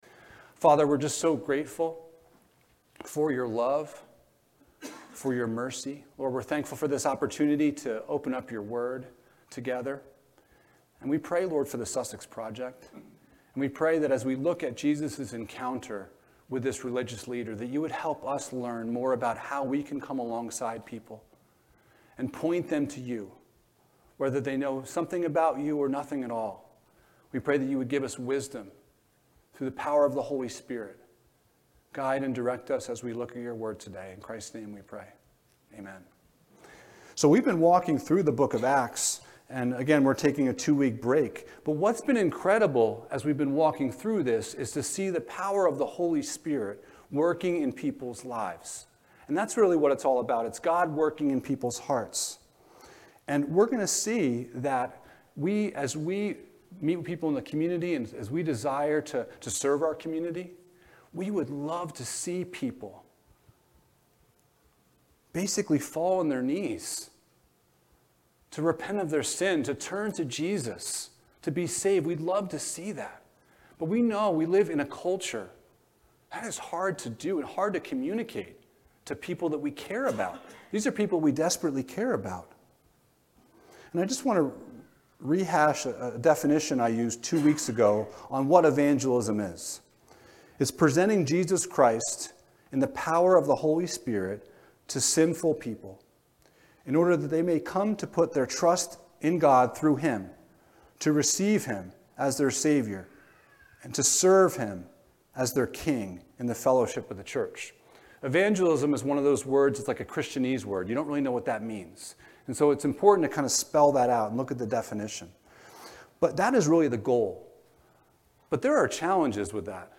Luke 10:25-37 Service Type: Sunday Morning Topics: The Good Samaritan « Testimony of a Father’s Love What Must I Do to be Saved?